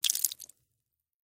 Звуки брызг крови
На этой странице представлена коллекция звуков брызг и капель крови в высоком качестве.
Слегка пролилась кровь